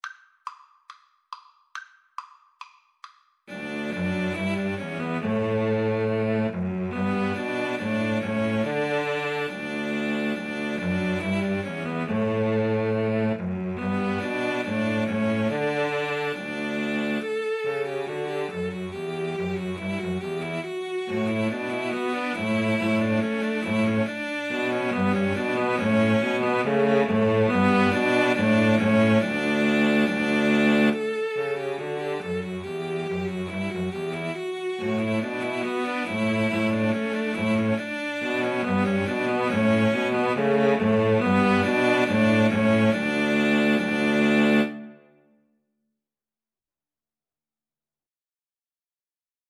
Cello Trio  (View more Easy Cello Trio Music)